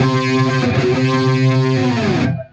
Index of /musicradar/80s-heat-samples/95bpm
AM_HeroGuitar_95-C02.wav